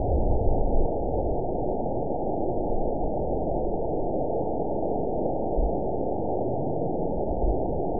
event 911700 date 03/06/22 time 15:45:04 GMT (3 years, 2 months ago) score 9.05 location TSS-AB02 detected by nrw target species NRW annotations +NRW Spectrogram: Frequency (kHz) vs. Time (s) audio not available .wav